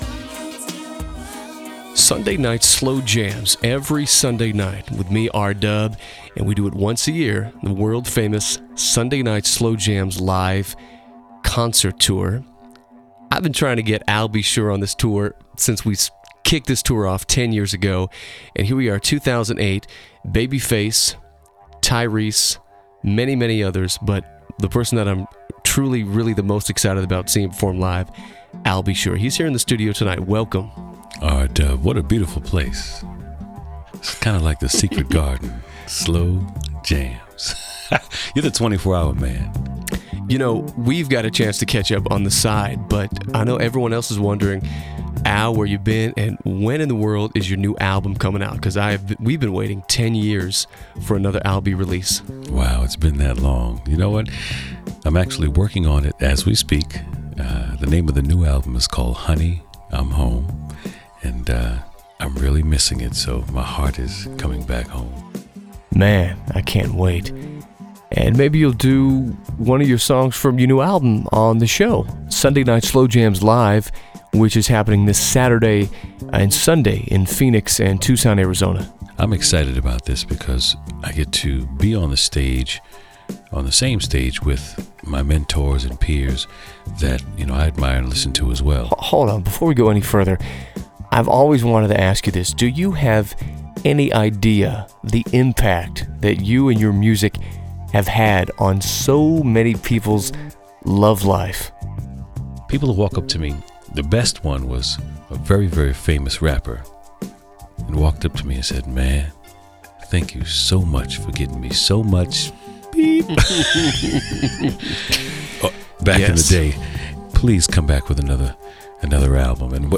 ALBINTERVIEW.mp3